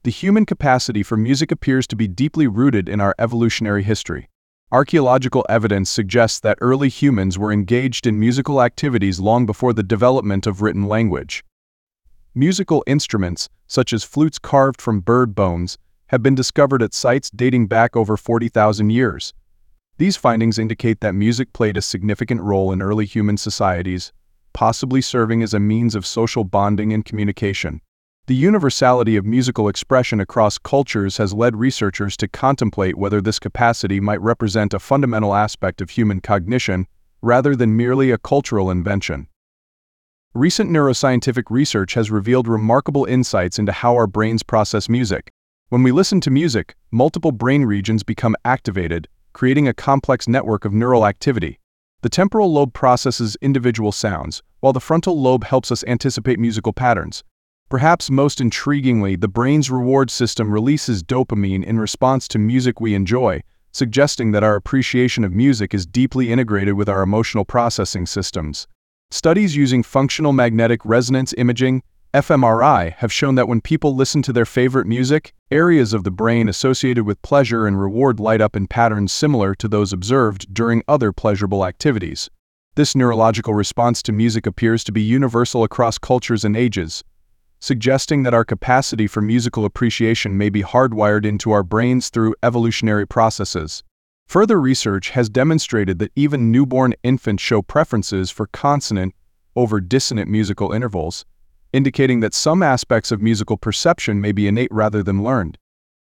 【朗読用音声】A